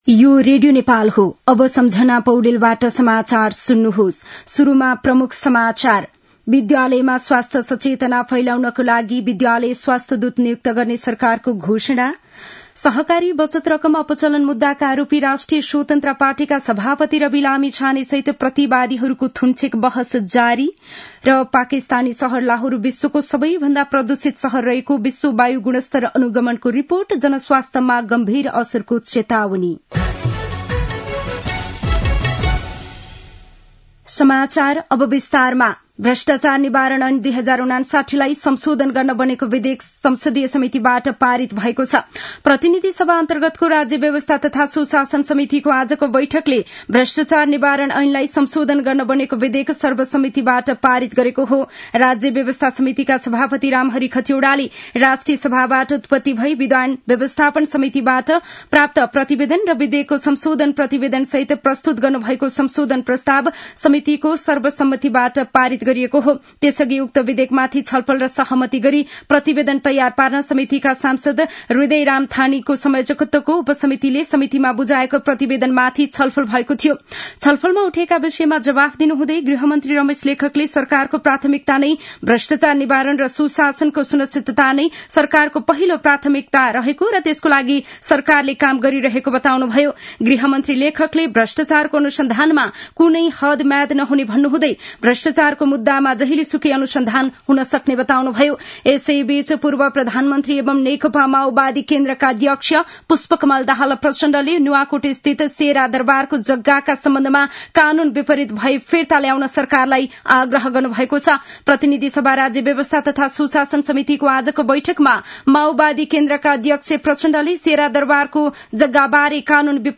दिउँसो ३ बजेको नेपाली समाचार : १९ पुष , २०८१
3-pm-nepali-news-1-1.mp3